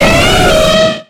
Cri de Donphan dans Pokémon X et Y.